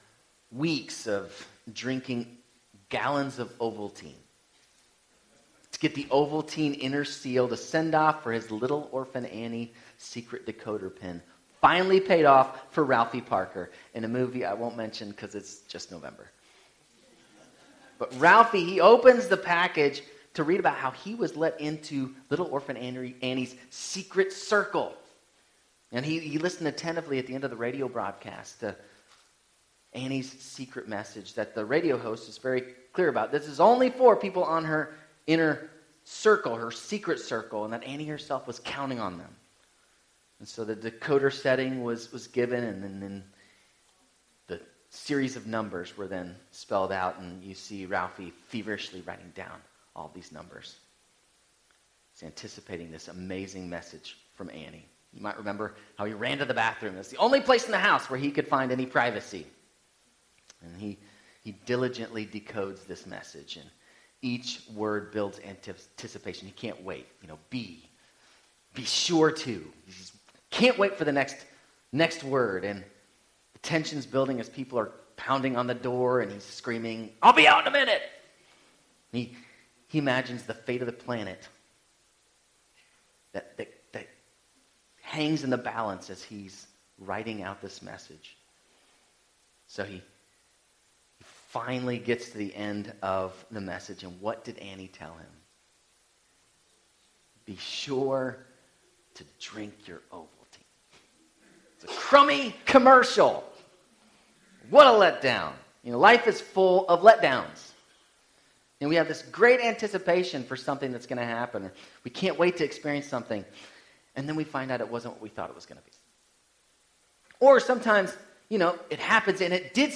Sermons – FBCNC